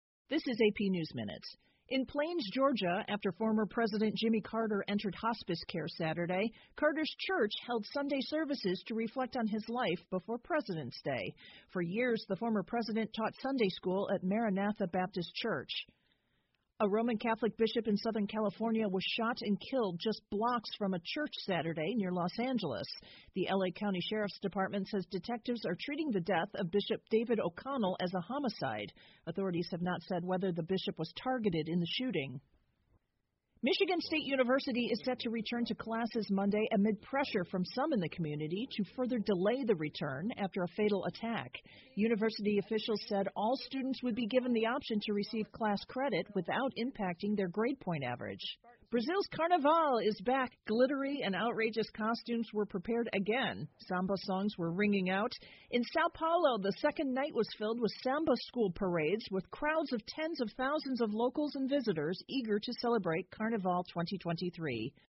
美联社新闻一分钟 AP 美国前总统吉米·卡特接受临终关怀 听力文件下载—在线英语听力室